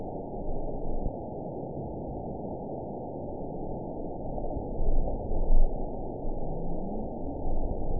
event 910992 date 02/06/22 time 02:25:30 GMT (3 years, 10 months ago) score 9.11 location TSS-AB02 detected by nrw target species NRW annotations +NRW Spectrogram: Frequency (kHz) vs. Time (s) audio not available .wav